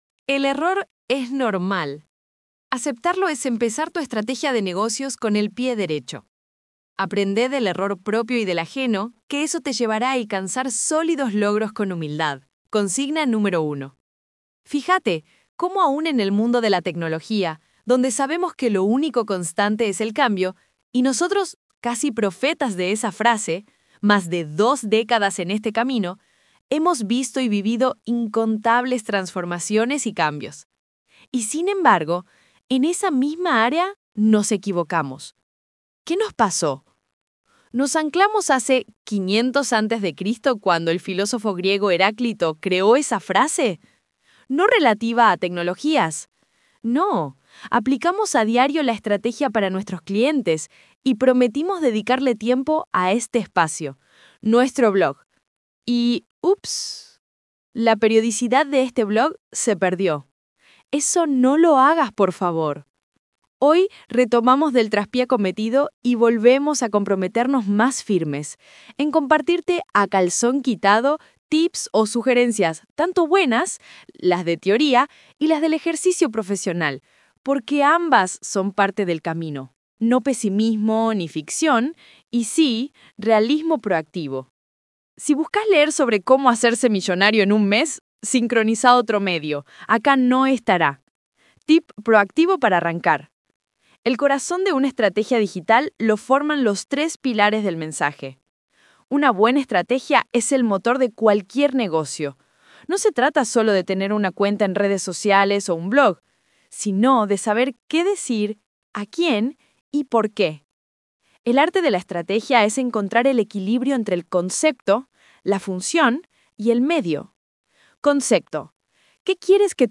Audio: Google AI Studio